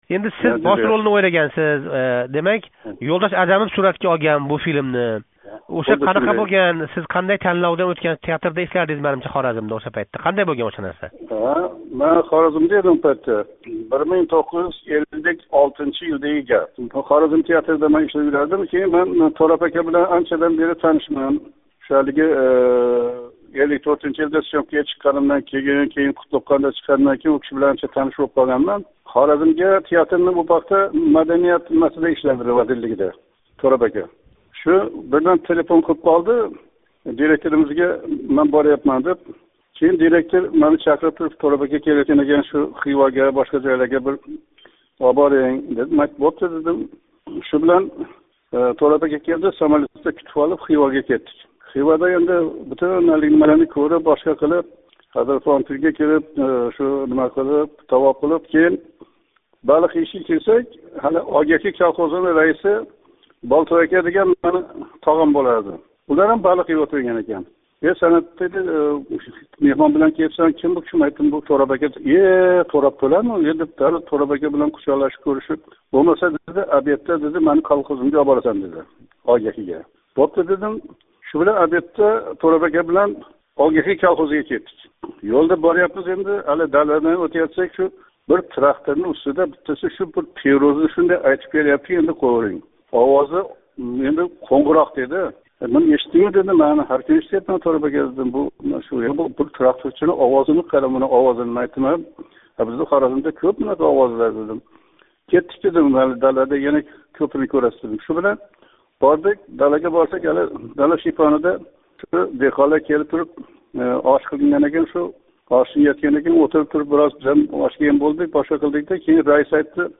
Санъат Девонов билан суҳбат